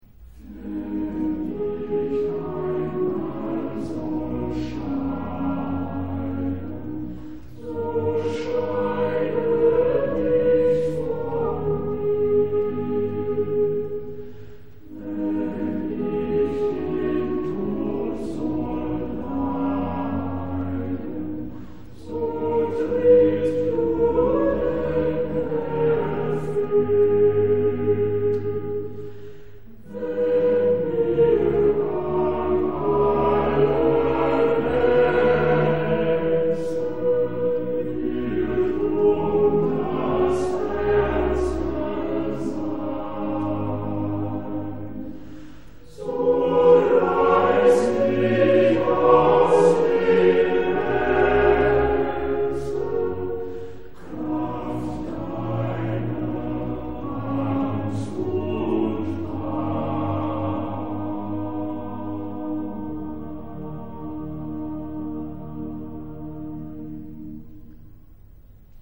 Bach Choral
Zie voor oplossing van zo'n vertraging het eind van de eerste, tweede en derde en vijfde koraalregel: Daar vindt op de derde en vierde tel geen harmoniewisseling plaats. Een versieringstoon in de sopraan staat bijvoorbeeld aan het eind van de tweede koraalregel (de B op de tweede helft van de derde tel is een wisseltoon ) Deze koraal wordt in de Matheus-passie een aantal keren gebruikt, met verschillende zettingen, en in verschillende toonsoorten: "Befiehl du deine Wege".